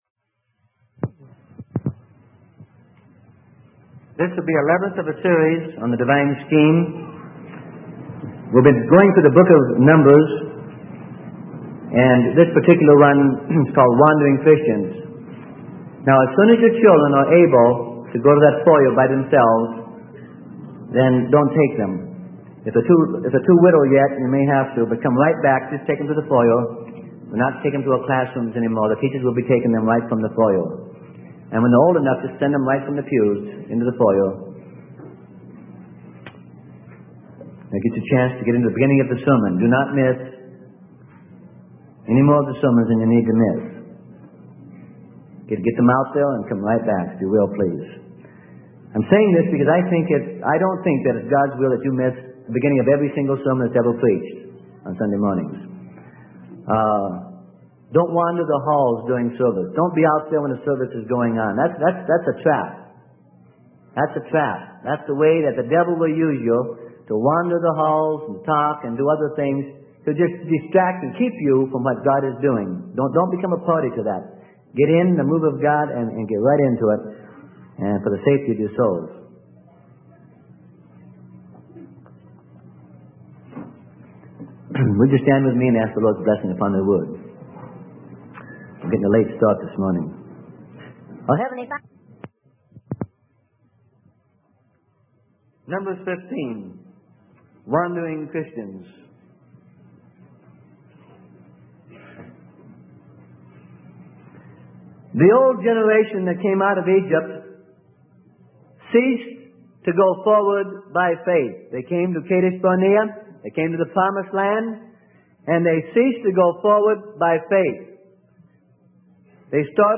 Sermon: God's Divine Scheme - Part 11 - Freely Given Online Library